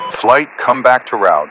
Radio-playerWingmanRejoin3.ogg